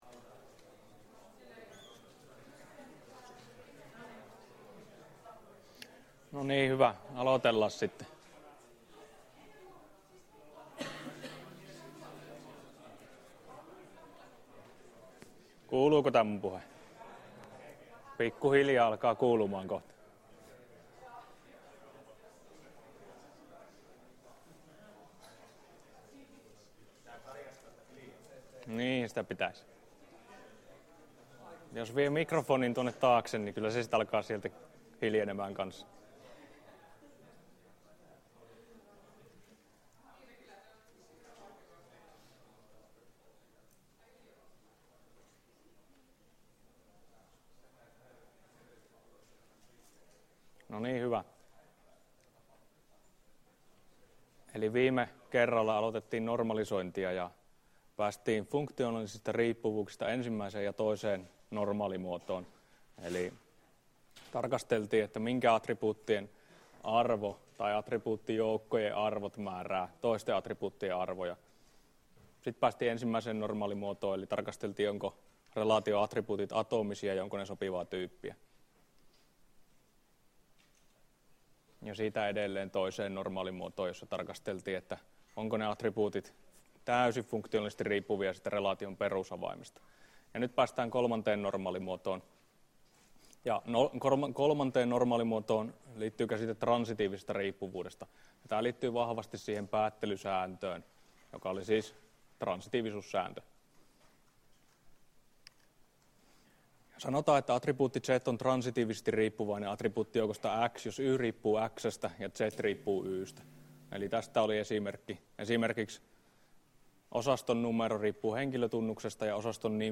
Luento 12 — Moniviestin